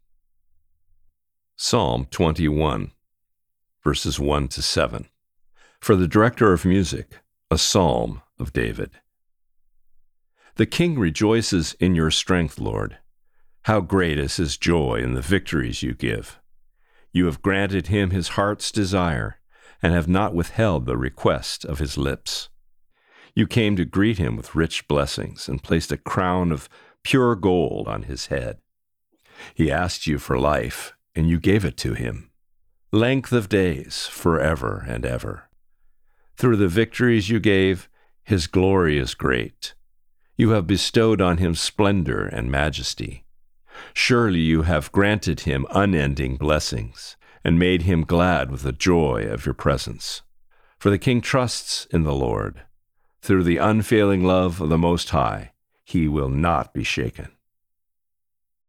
Today’s Reading: Psalm 21:1-7